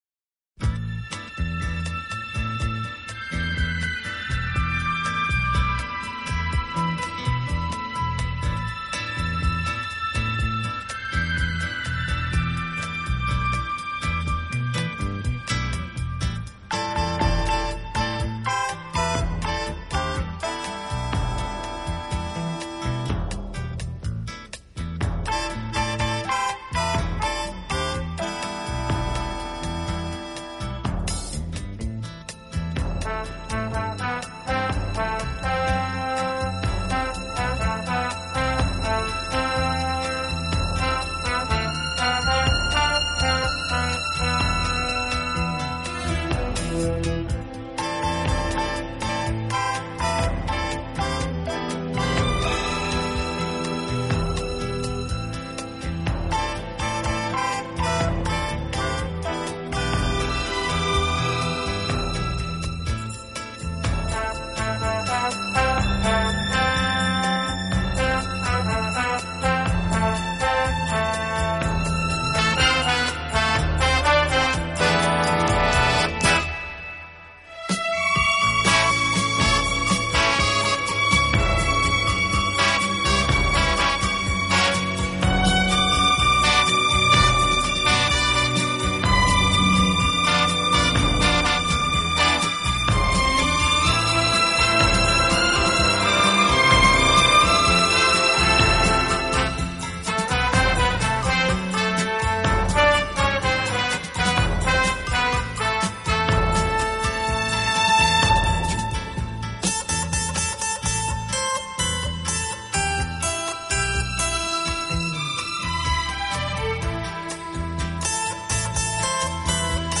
“清新华丽，浪漫迷人”